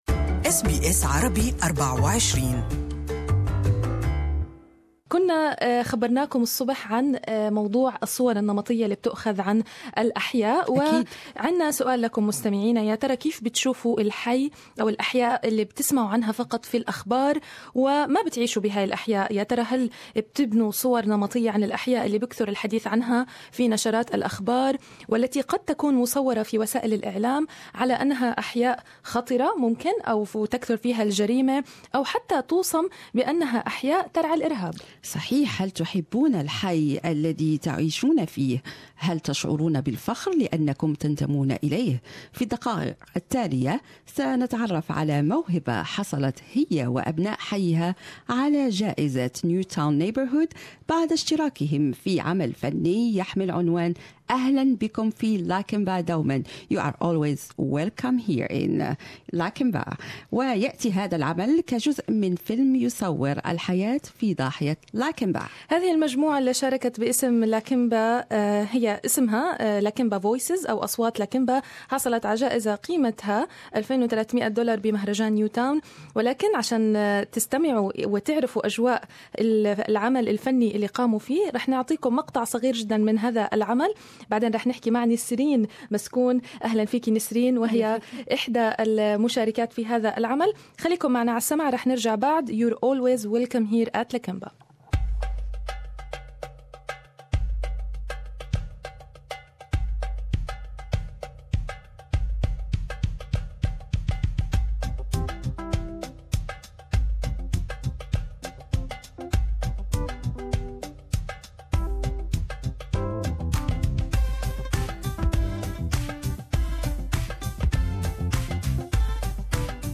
SBS Arabic 24 studio